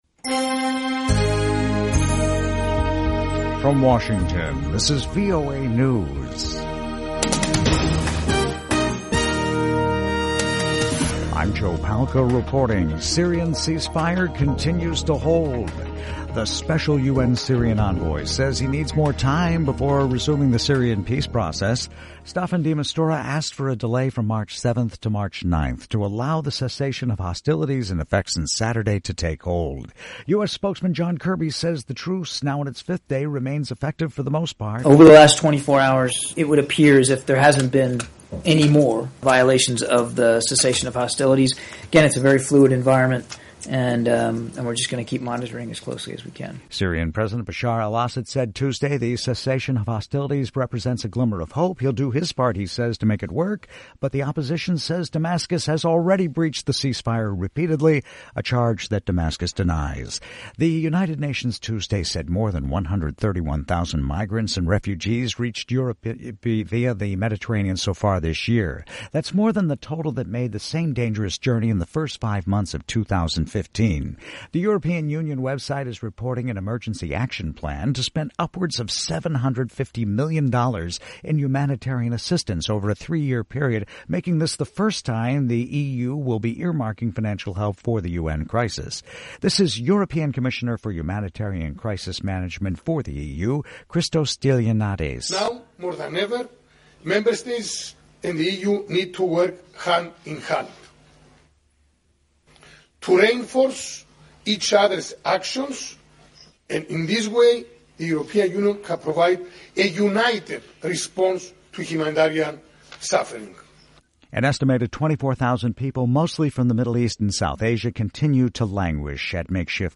VOA English Newscast: 1500 UTC March 2, 2016
by VOA - Voice of America English News